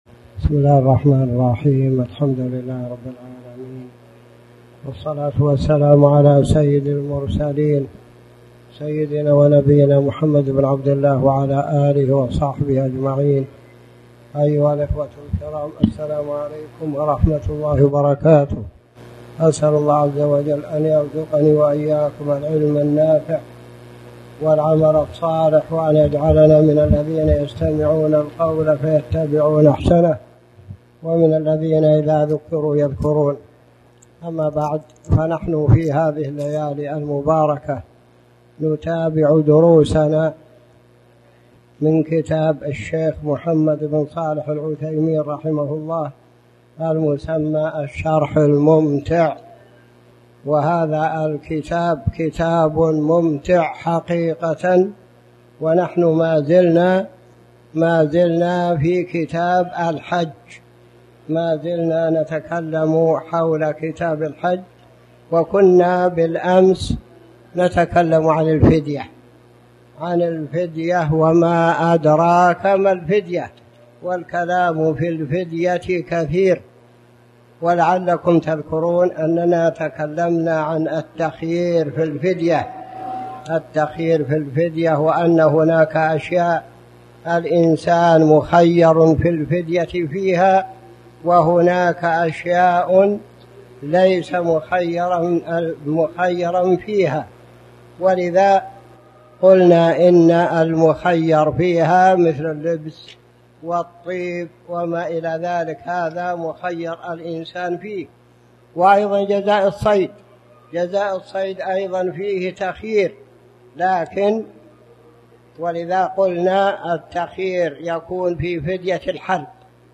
تاريخ النشر ١٩ ذو الحجة ١٤٣٨ هـ المكان: المسجد الحرام الشيخ